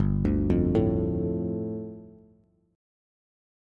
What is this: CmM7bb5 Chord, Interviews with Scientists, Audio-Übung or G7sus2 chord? G7sus2 chord